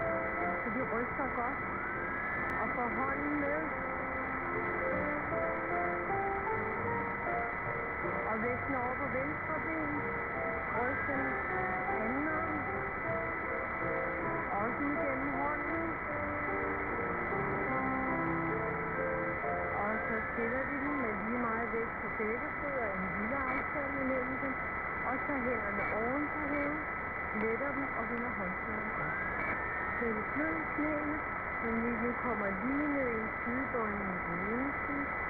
Радио Дании 243 кГц днем.
243_dania.wav